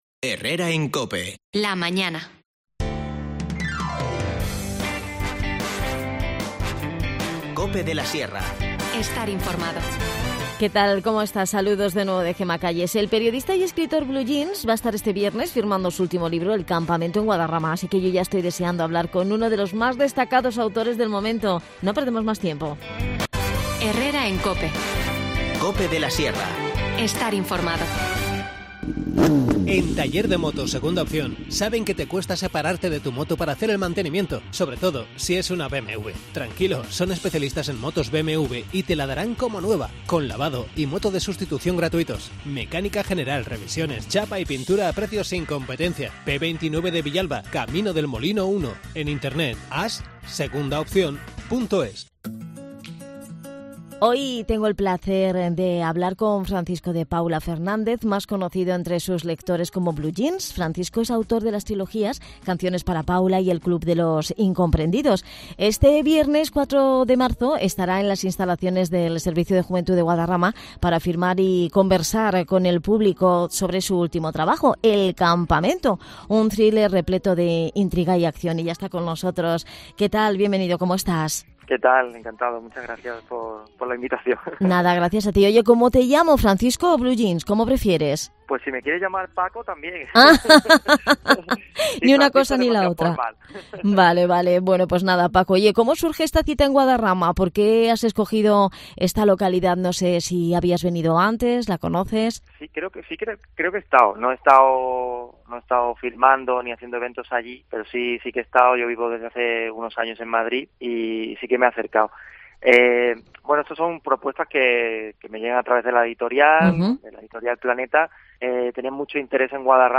Charlamos con Francisco de Paula Fernández más conocido como Blue Jeans.